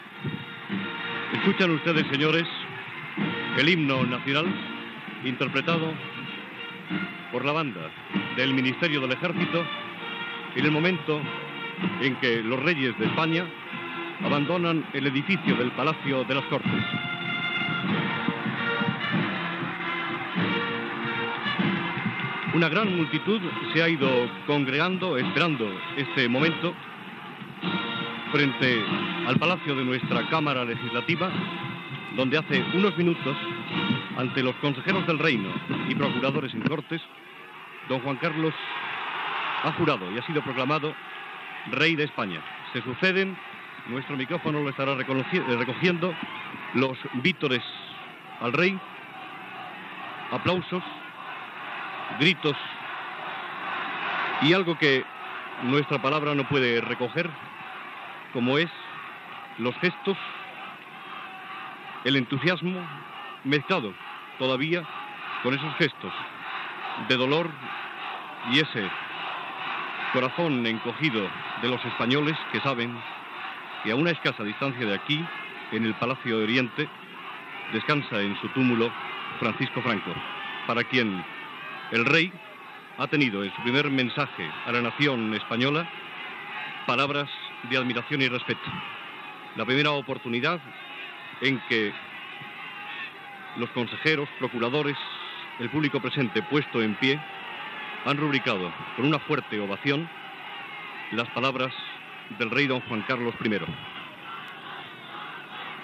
Narració de la sortida del rei Juan Carlos I de l'edifici del Palacio de las Cortes, a la carrera de Sant Jerónimo de Madrid, després de la seva coronació
Informatiu